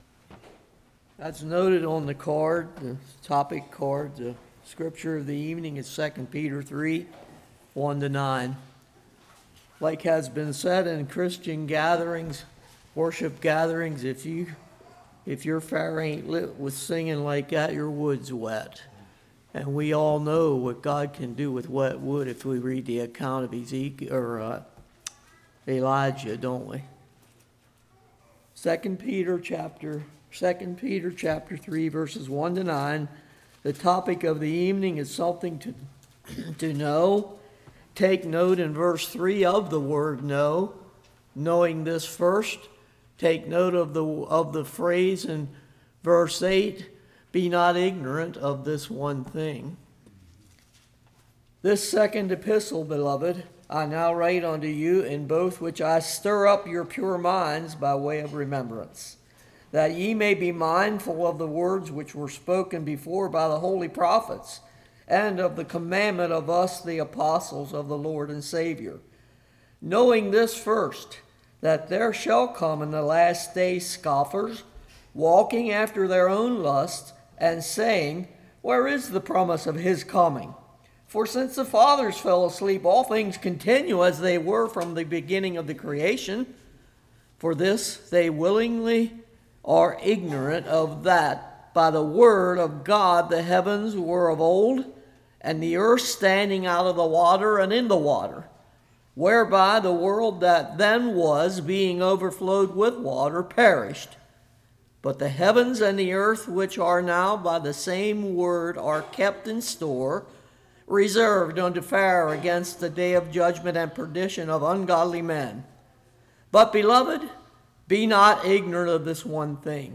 2 Peter 3:1-9 Service Type: Revival Ignorance is better than apostasy There will be mockers Jesus is coming